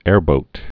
(ârbōt)